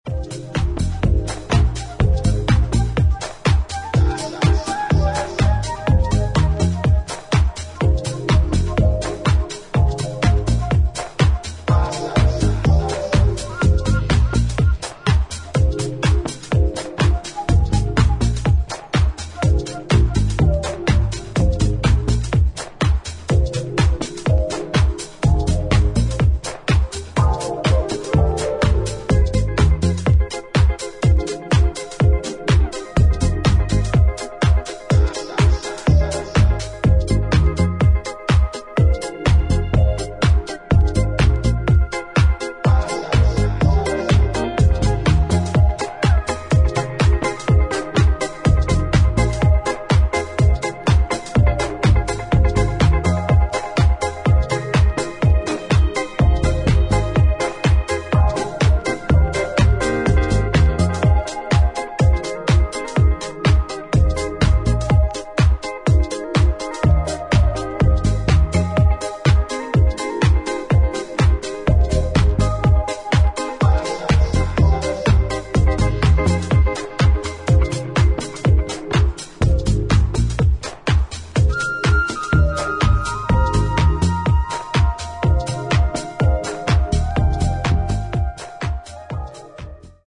抜けの良いタイトな4つ打ちにダビーな生楽器の音色が絡む、ディープでファンキーなウェストコースト・スタイルハウス。